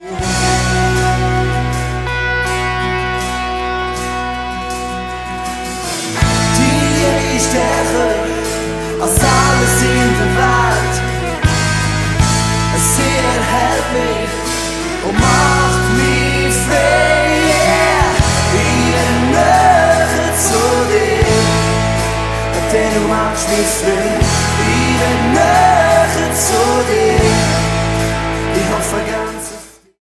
Diese Worship-CD wurde am Crea Meeting live aufgenommen.